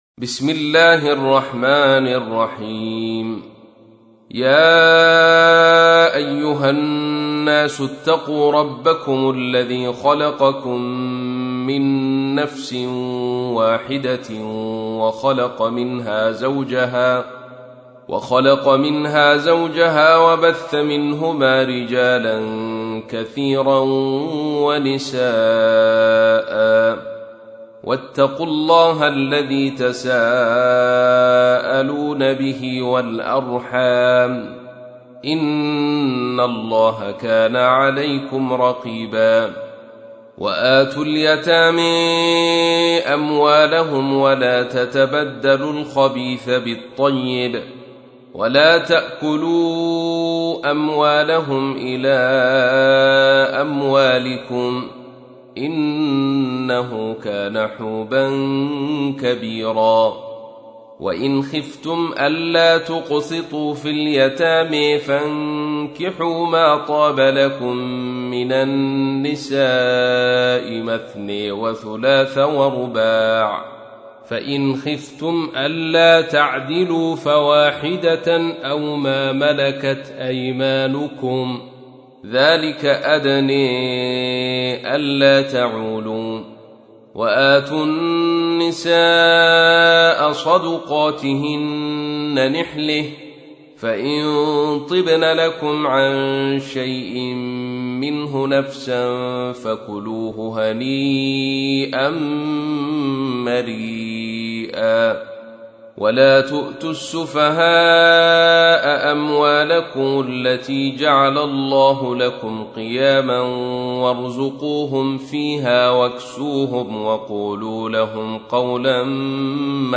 تحميل : 4. سورة النساء / القارئ عبد الرشيد صوفي / القرآن الكريم / موقع يا حسين